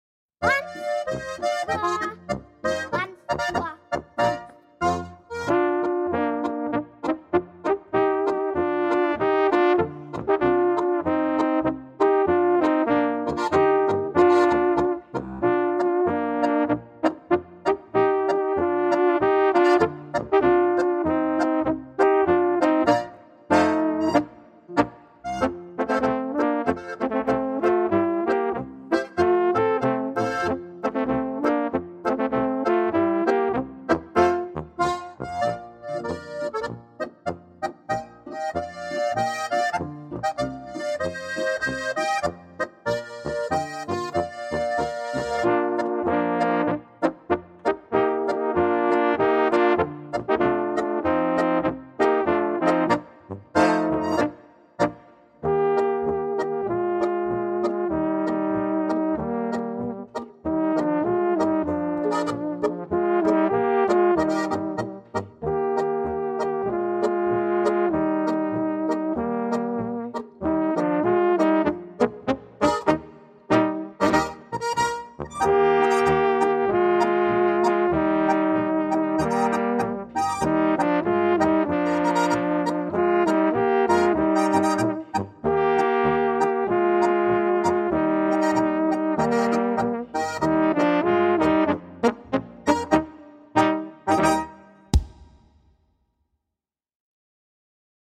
Besetzung: Trp